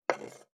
551魚切る,肉切りナイフ,まな板の上,包丁,ナイフ,調理音,料理,
効果音